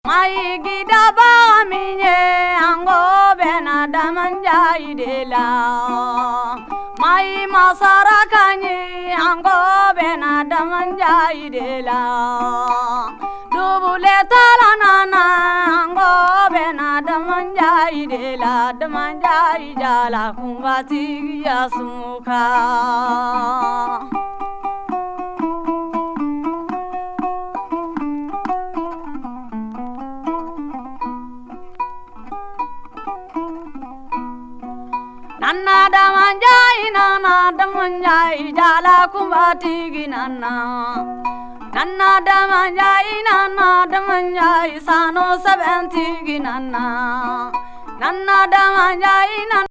からの一枚!歌声に魂が宿る、スピリチュアルで宗教的な傑作。